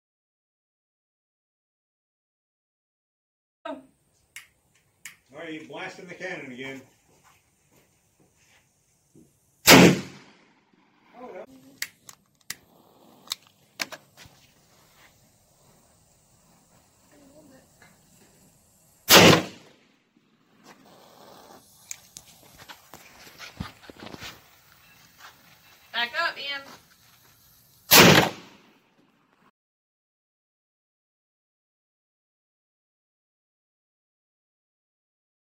Firing a brass cannon